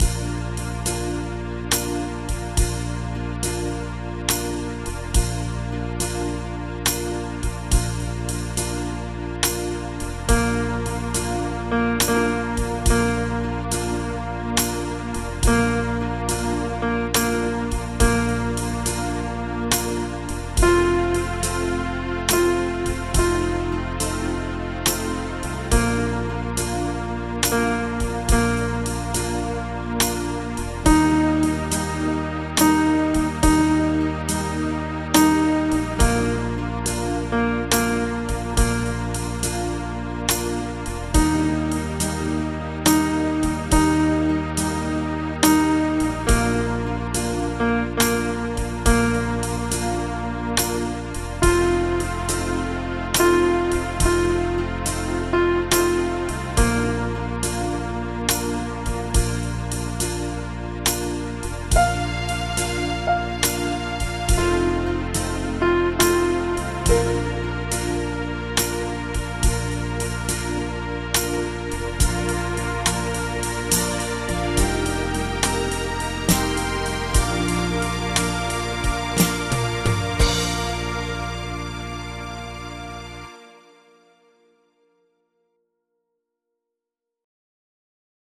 Audio Midi Bè Bass: download